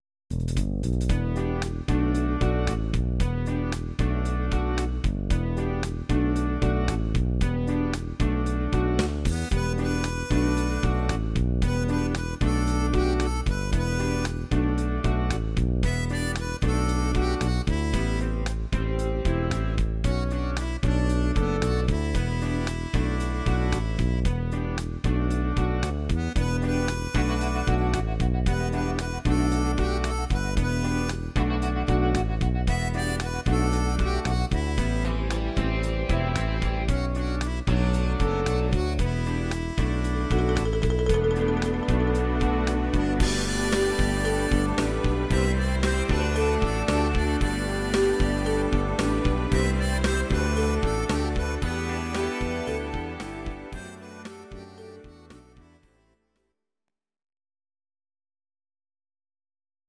Audio Recordings based on Midi-files
cover